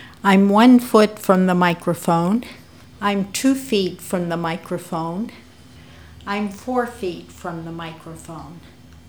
Inverse square law (1609.0K) – This is a voicer that demonstrates the change in volume as the person moves further from the microphone.